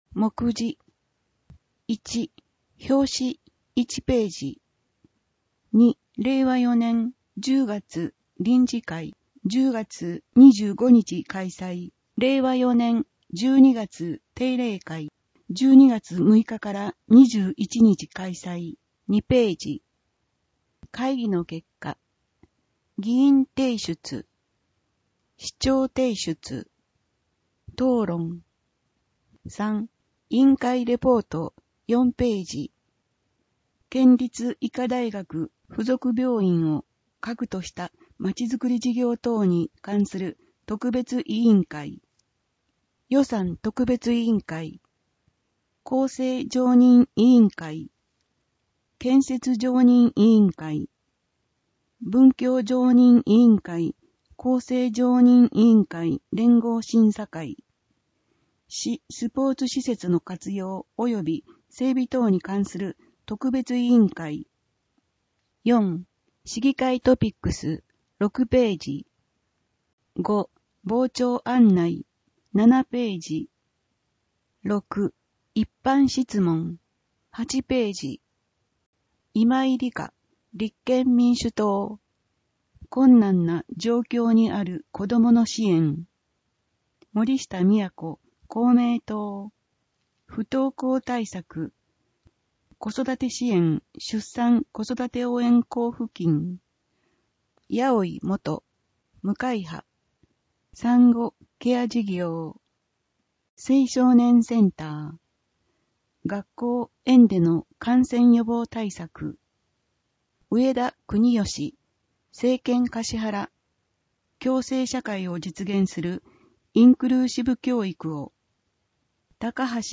音訳データ
かしはら市議会のいま224号 (PDFファイル: 6.2MB) 音訳データ かしはら市議会のいま第224号の音訳をお聞きいただけます。 音訳データは、音訳グループ「声のしおり」の皆さんが音訳されたものを使用しています。